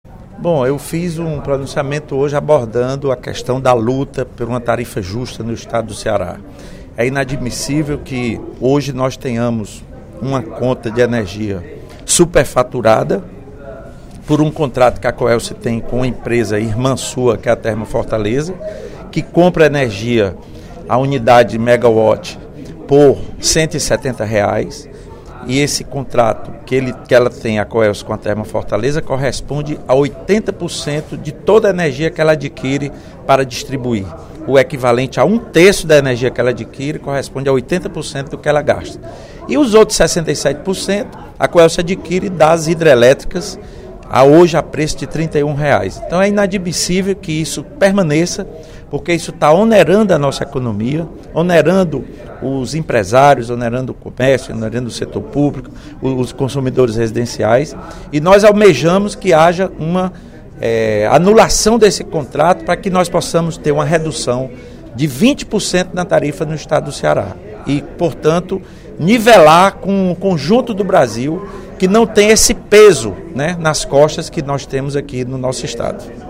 O deputado Lula Morais (PCdoB) defendeu, nesta terça-feira (02/04), durante o primeiro expediente, a necessidade de se realizar uma campanha pela redução de 20% na tarifa de energia.